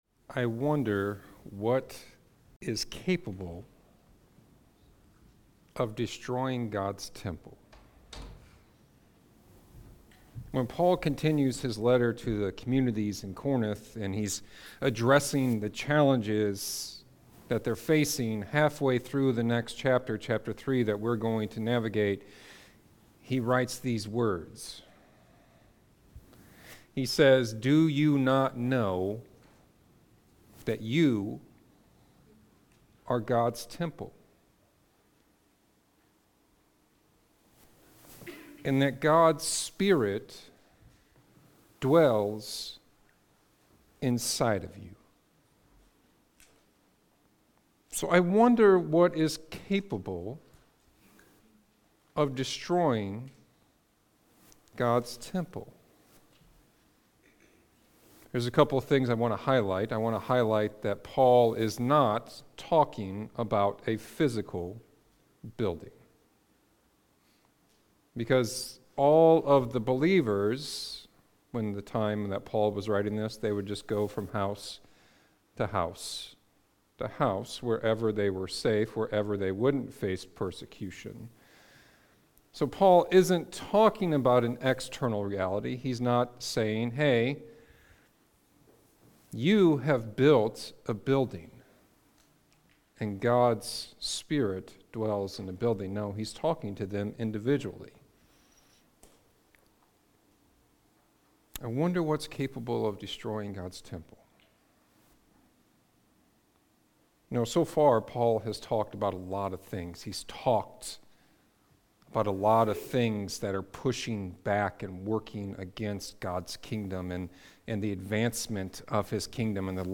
Sermons | Beacon Church